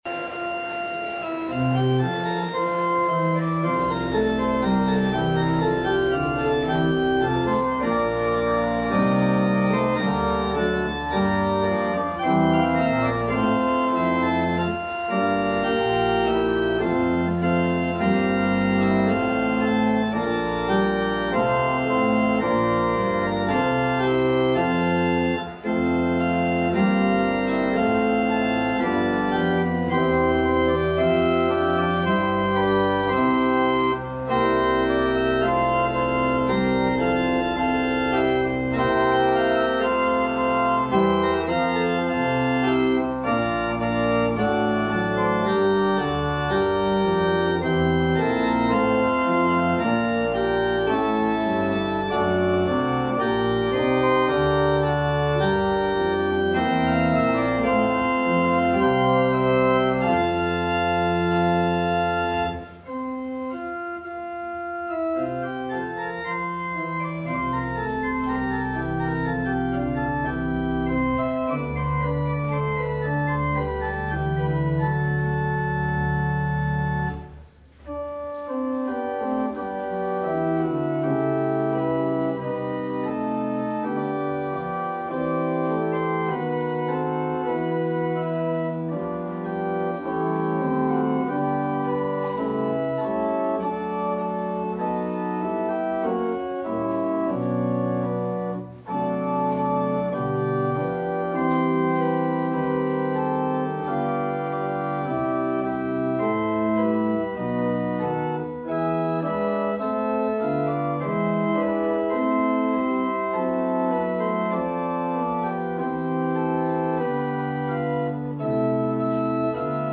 Christmas Eve: Candlelight Service (9pm)